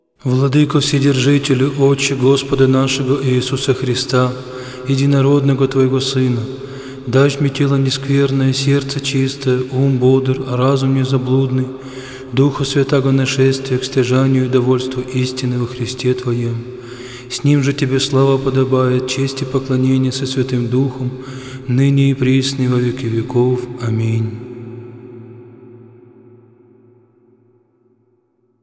Псалмы царя-пророка Давида Диск 1  10 Прослушай всички композиции от този стил музика Прослушай всички композиции от този стил музика в случаен ред Проповед
10-Молитва.mp3